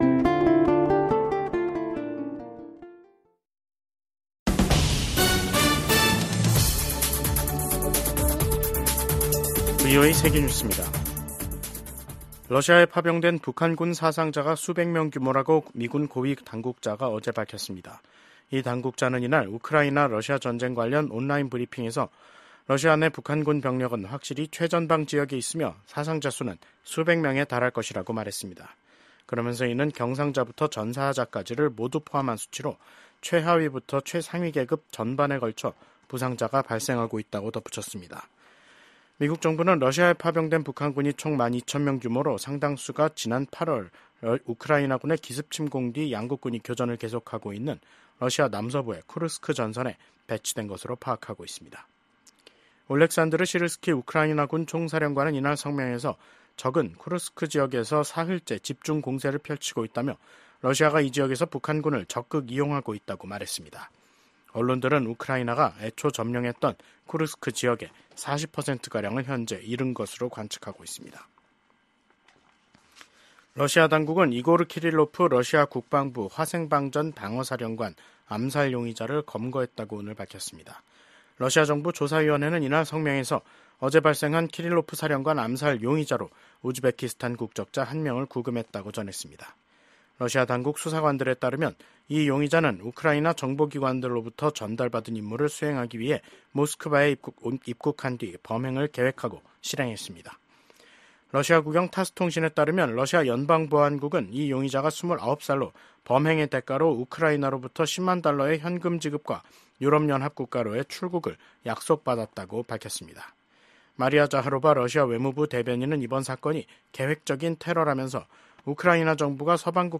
VOA 한국어 간판 뉴스 프로그램 '뉴스 투데이', 2024년 12월 18일 2부 방송입니다. 러시아에 파병된 북한군에서 수백 명의 사상자가 발생했다고 미군 고위 당국자가 밝혔습니다. 미국 국무부는 한국 대통령 탄핵소추안 통과와 관련해 한국 헌법 절차가 취지대로 작동하고 있다고 평가했습니다.